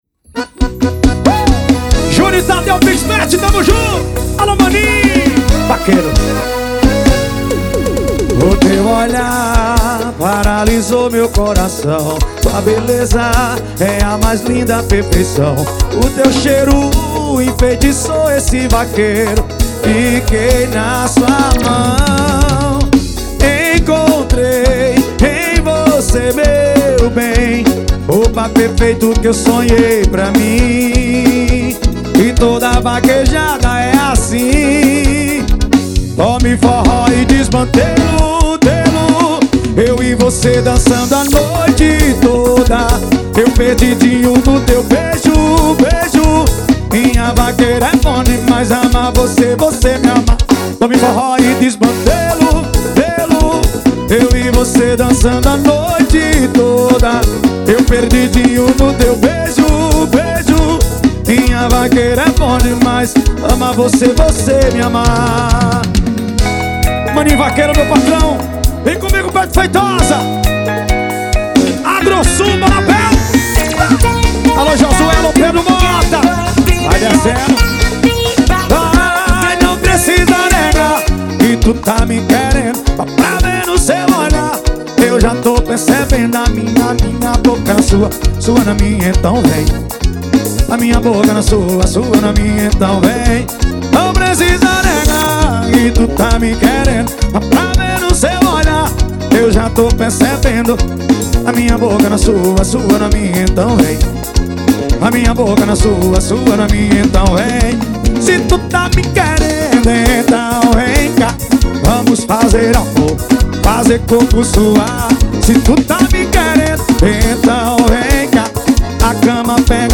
2024-02-14 18:42:51 Gênero: FORRO Views